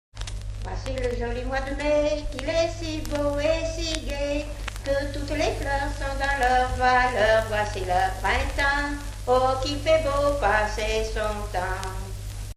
Source : Chansons et contes de Haute-Loire, L'enquête phonographique de 1946, page 405
Origine : Auvergne (Haute-Loire) Année de l'arrangement : 2014
Source : Chansons et contes de Haute-Loire , L'enquête phonographique de 1946 , page 405 Chanson de quête.